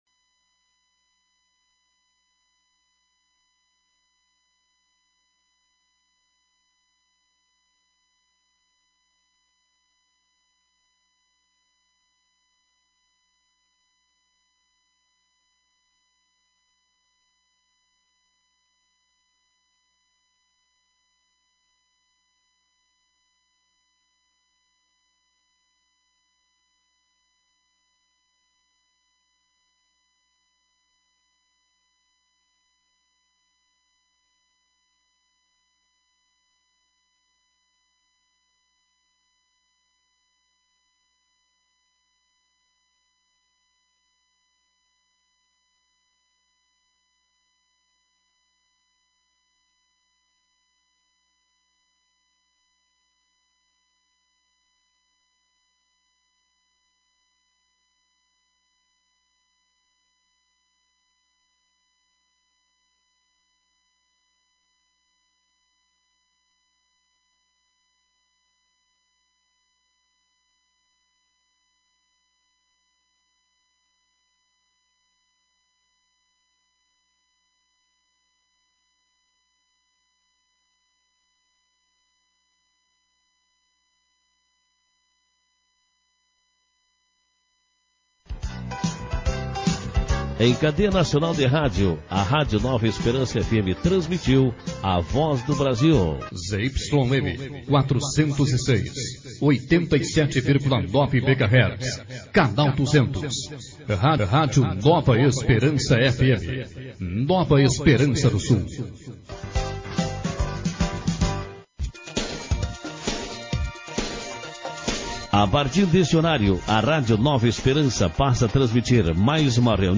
Sessões Plenárias